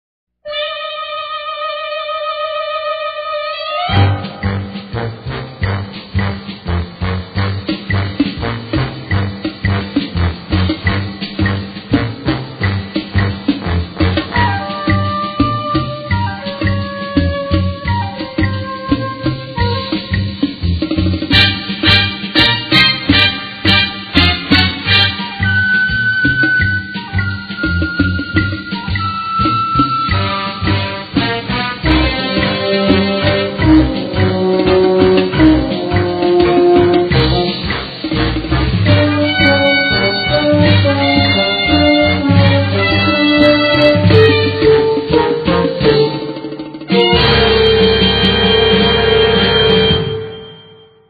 Movie Theme Song